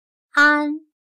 /ān/tranquilo; estable.